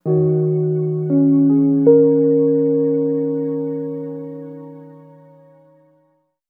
Reverb Piano 12.wav